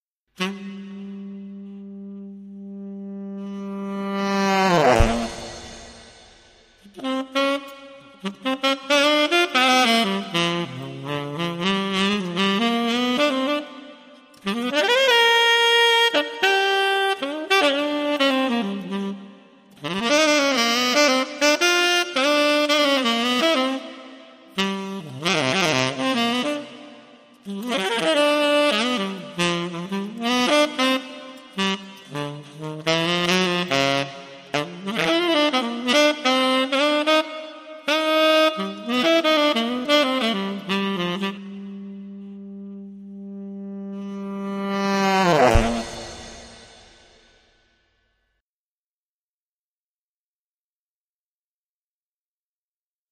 basso elettrico in brani 7